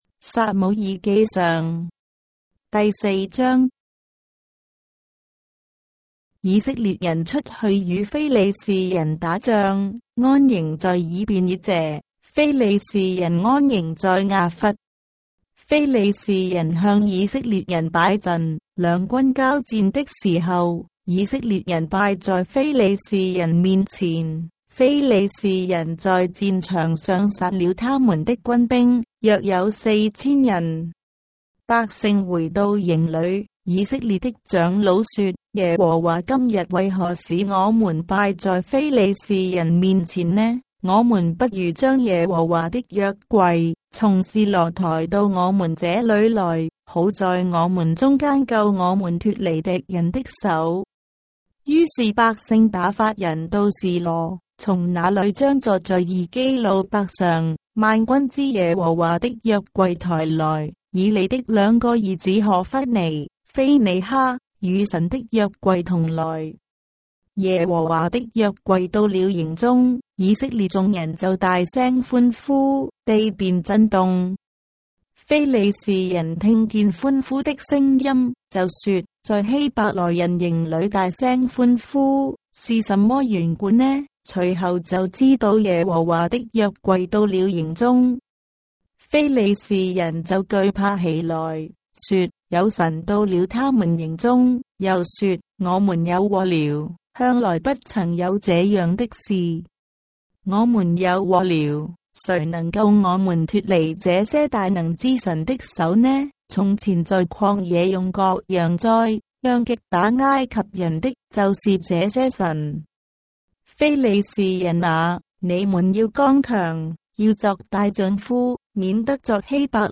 章的聖經在中國的語言，音頻旁白- 1 Samuel, chapter 4 of the Holy Bible in Traditional Chinese